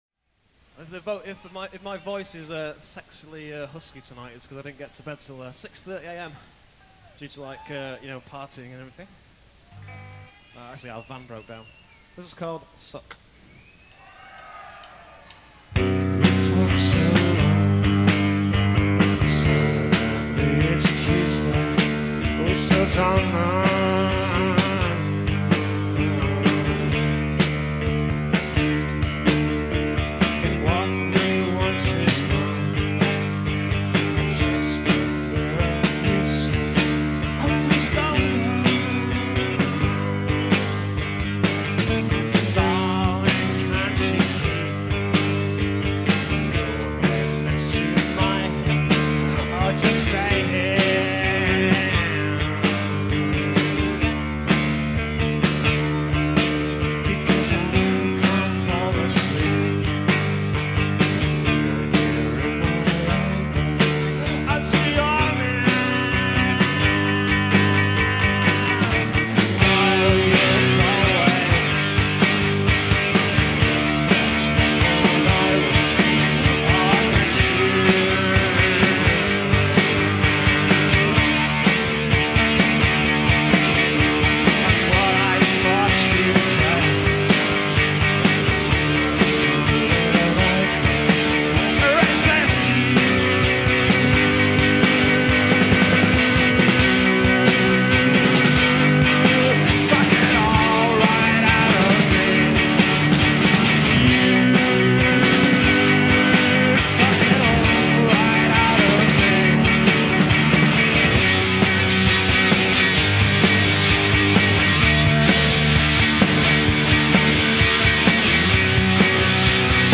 Live at the Astoria Theater, London